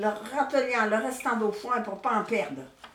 Collectif - ambiance
Catégorie Locution